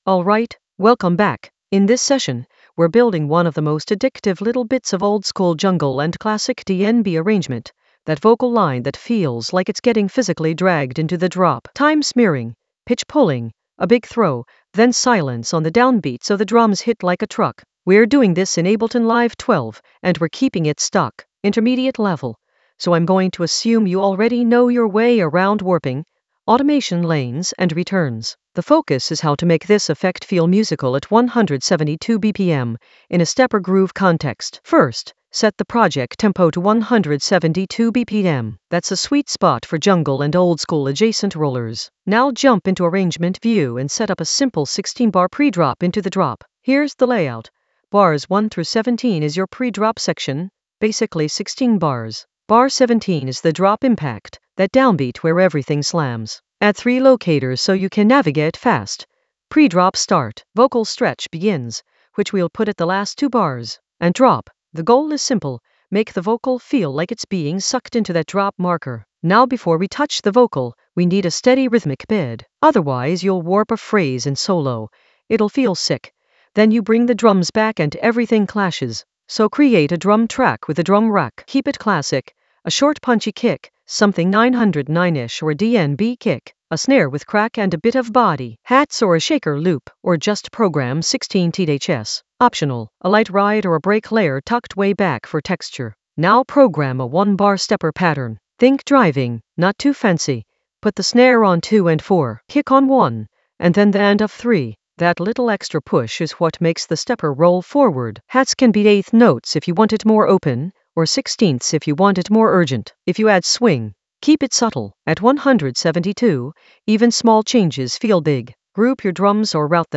Narrated lesson audio
The voice track includes the tutorial plus extra teacher commentary.
An AI-generated intermediate Ableton lesson focused on Stepper session: drop stretch in Ableton Live 12 for jungle oldskool DnB vibes in the Vocals area of drum and bass production.